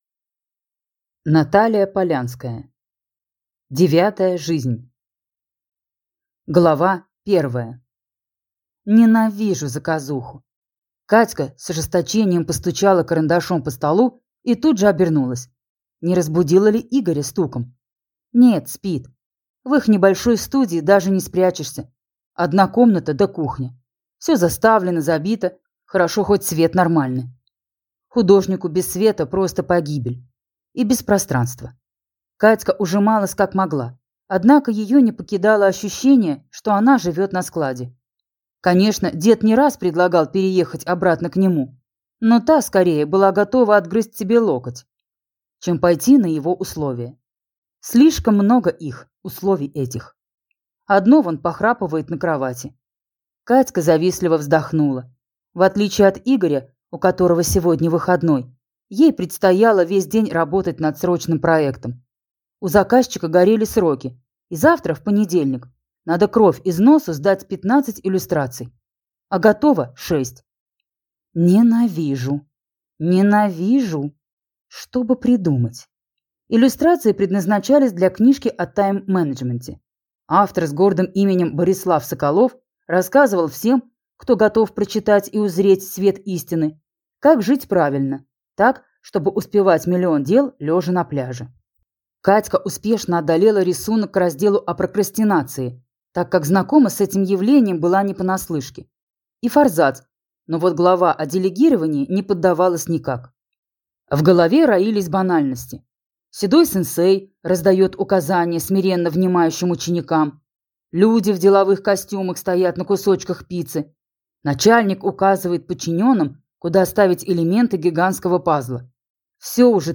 Аудиокнига Девятая жизнь | Библиотека аудиокниг
Прослушать и бесплатно скачать фрагмент аудиокниги